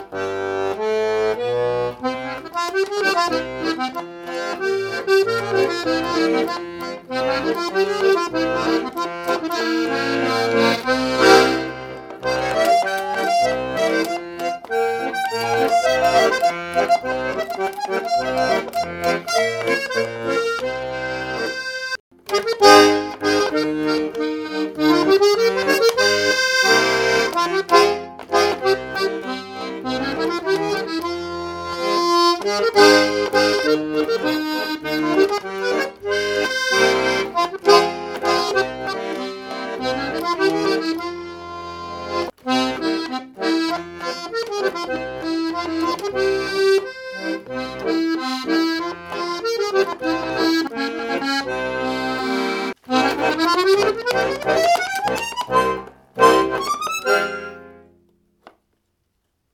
SOPRANI PAOLO (gebraucht) Historisches Knopfakkordeon C-Griff, 3-reihig, 46 Töne, 80 Bässe Typ: Musette, 80 Bässe
Preis: 499,- Euro Baujahr: 1930 ca. Stimmplatten: Dural Farbe Gehäuse: Grün-Perloid Farbe Balg: bunt Gewicht (kg): 7,5 Frequenz / Tremolofrequenz von a=440/sec: 435 / + 8 / -16 SOUNDTRACKS (mp3): track 1
akkk3r-SOPRANI-gruen-klezmer.mp3